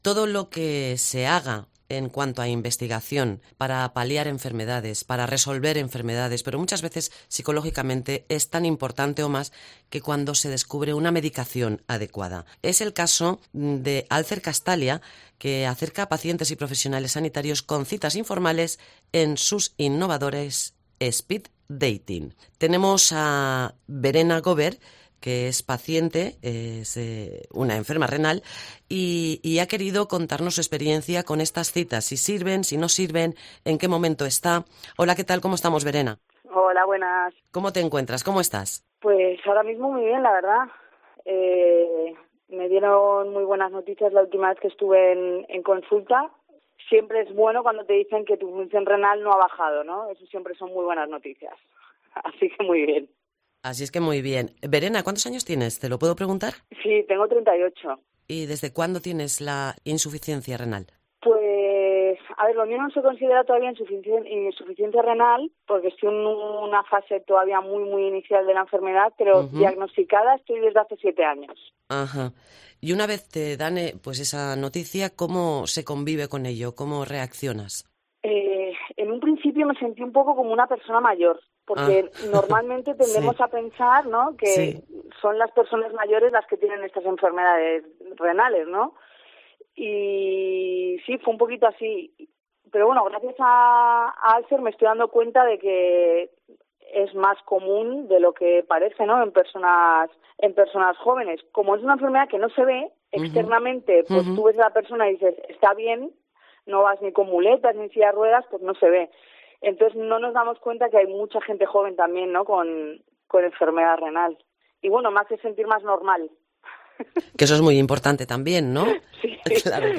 Una enferma renal nos explica en COPE cómo ha funcionado esta jornada.